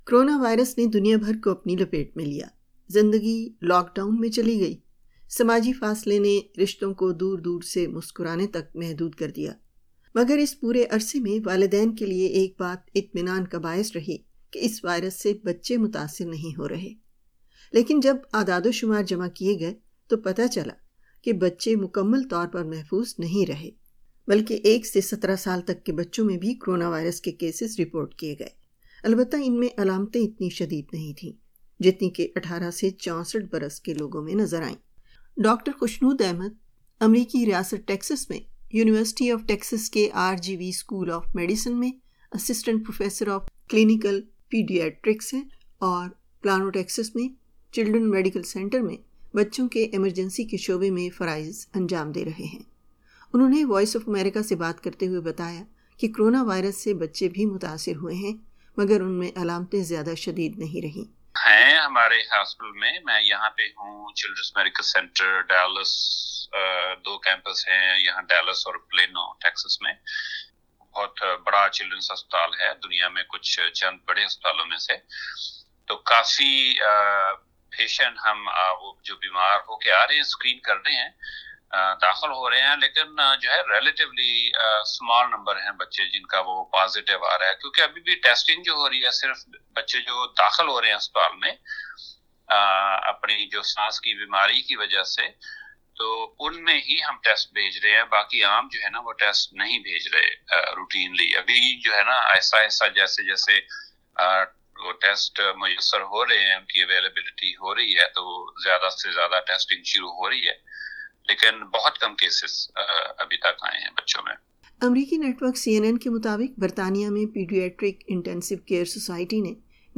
انہوں نے وائس آف امریکہ سے بات کرتے ہوئے بتایا کہ کرونا وائرس سے بچے بھی متاثر ہوئے ہیں۔